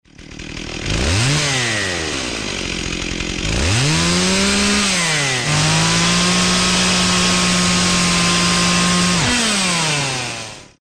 zvuki-benzopily_003
zvuki-benzopily_003.mp3